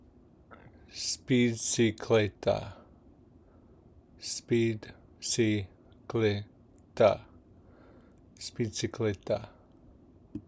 Pronunciation Guide
Speedcicleta-Pronunciation.m4a